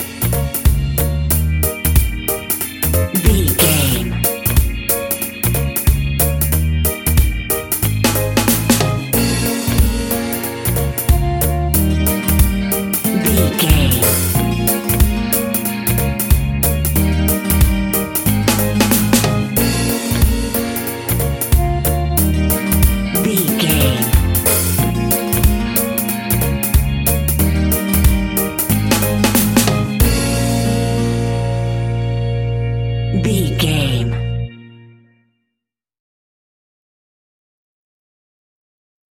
A fast and speedy piece of Reggae music, uptempo and upbeat!
Uplifting
Aeolian/Minor
B♭
dub
laid back
chilled
off beat
drums
skank guitar
hammond organ
percussion
horns